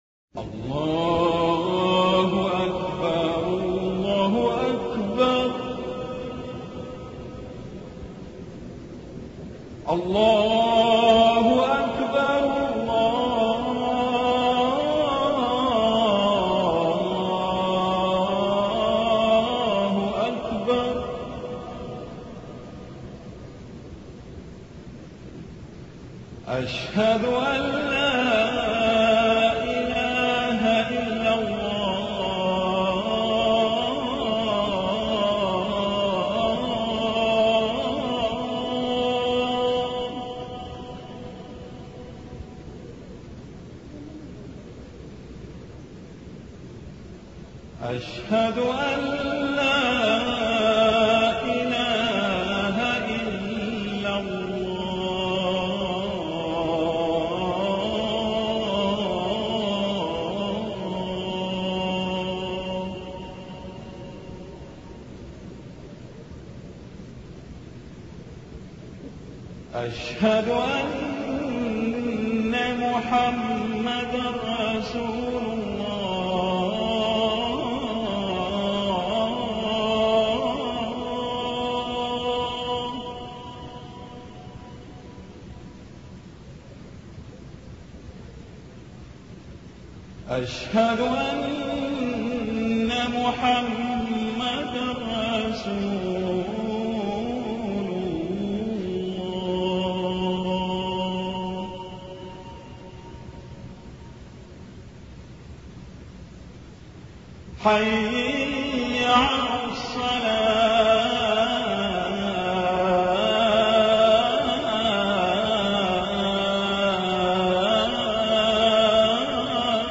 نوادر الأذان 🕋